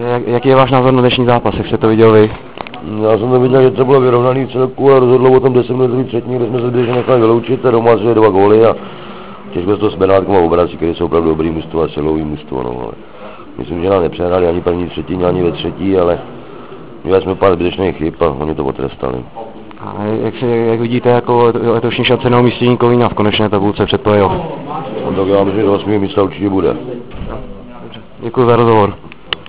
Zvukový záznam ohlasu